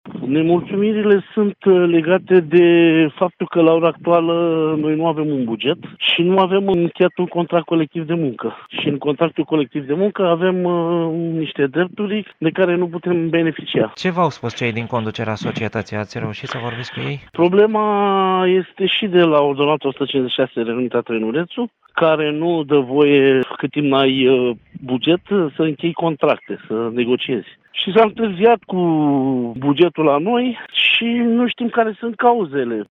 Contactat telefonic de Radio Constanța